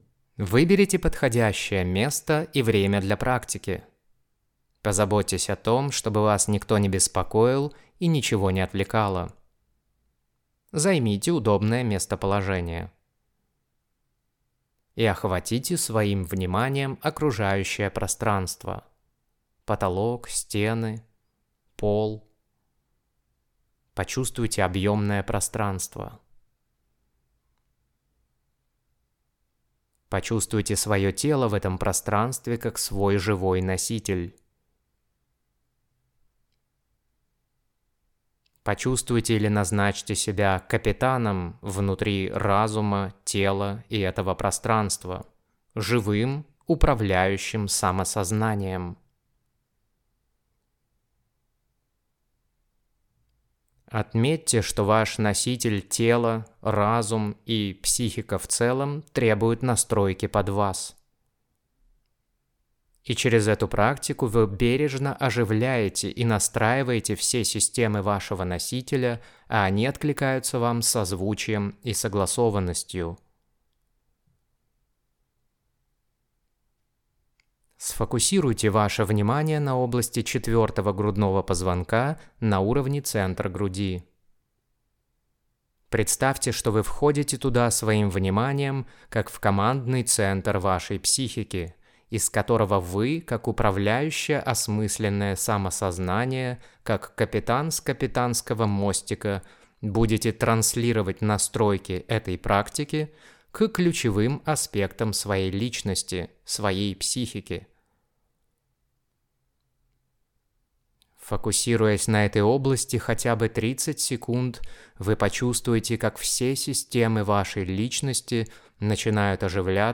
Практика в аудио версии для настройки функции самоочищения психики от негатива.